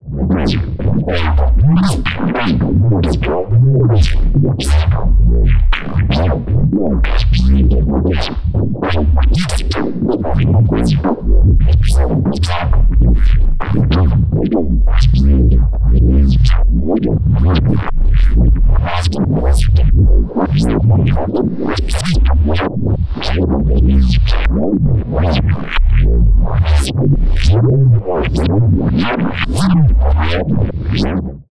Weird neuro fx.wav